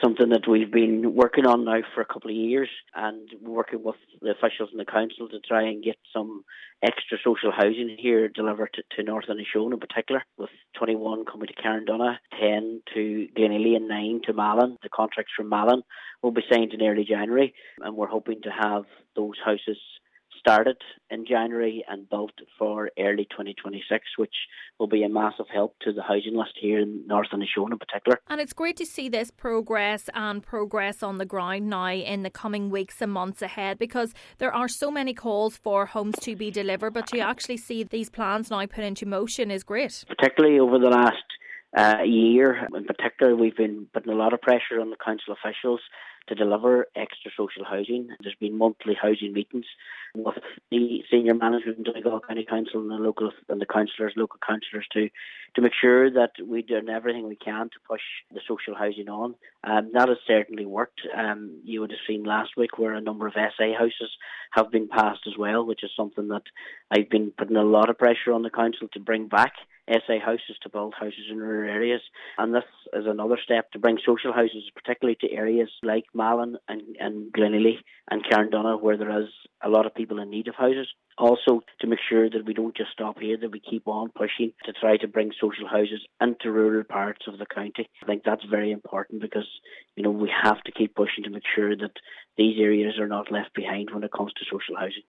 Councillor Martin McDermott says it’s imperative there is a focus on delivering social homes in rural parts: